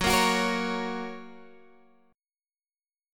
Gb Chord
Listen to Gb strummed